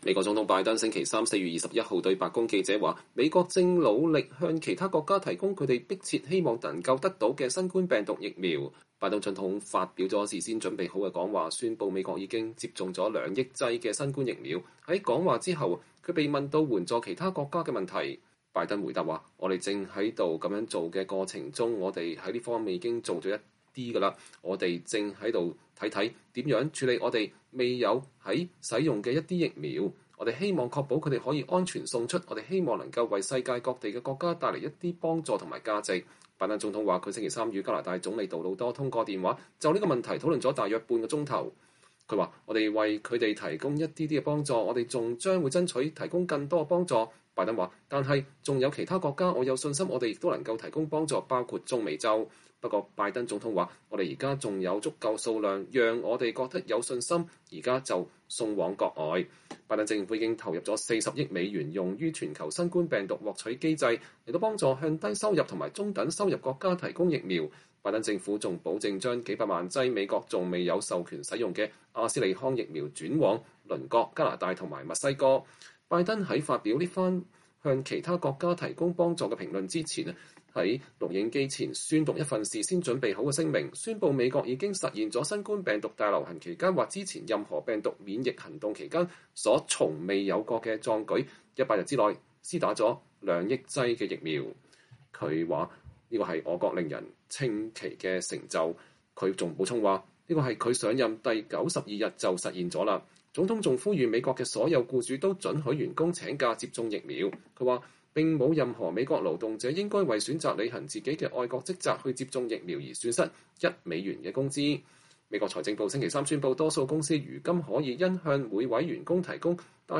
拜登總統在白宮談新冠疫苗接種。(2021年4月21日)